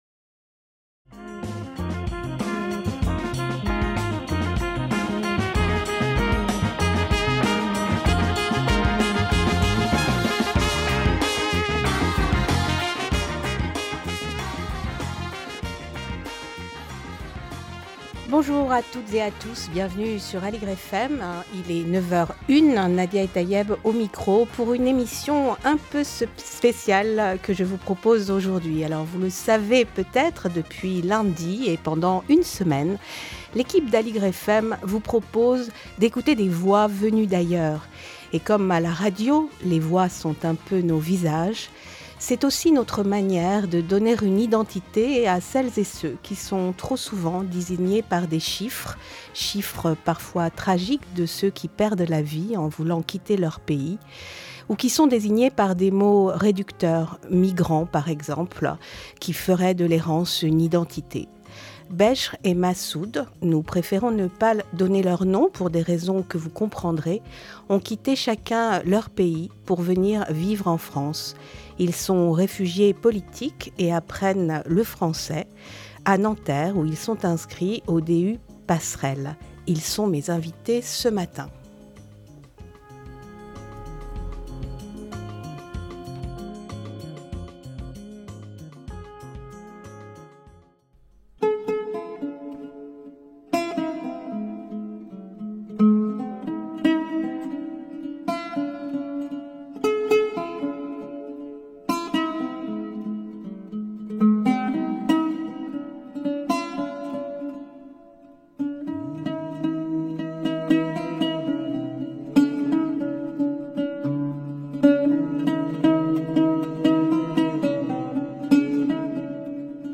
Un moment de partage placé sous le signe de la musique et de la poésie qu'ils ont choisies, celle de Nizar Qabbani, immense poète syrien, et celle de Rabindranath Tagore, poète bangladais, romancier dramaturge et peintre qui parcourut le monde. Des lectures en arabe, en bengali et en français.